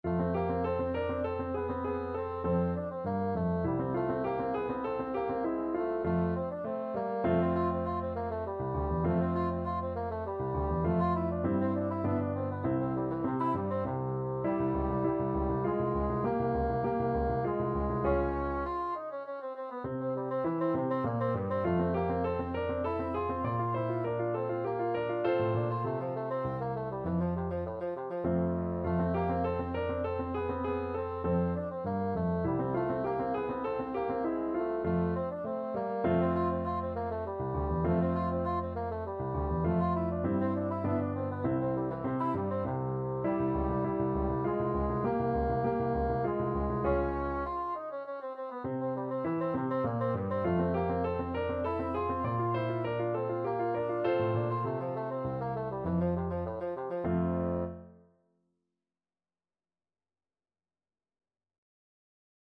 3/4 (View more 3/4 Music)
Classical (View more Classical Bassoon Music)